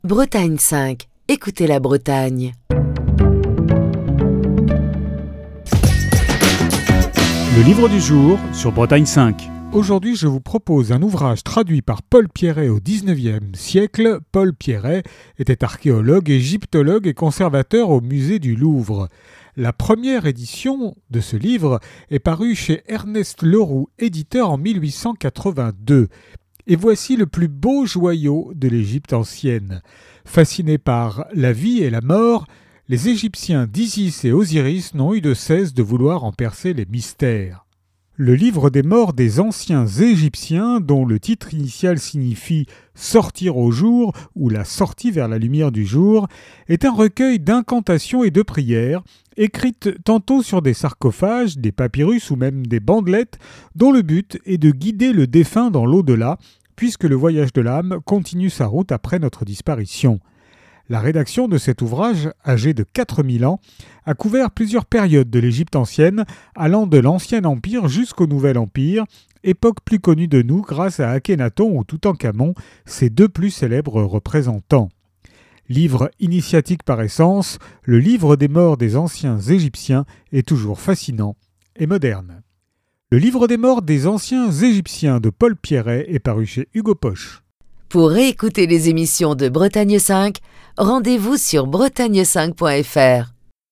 Chronique du 25 décembre 2023.